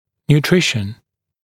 [njuː’trɪʃn][нйу:’тришн]питание